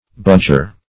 budger - definition of budger - synonyms, pronunciation, spelling from Free Dictionary Search Result for " budger" : The Collaborative International Dictionary of English v.0.48: Budger \Budg"er\, n. One who budges.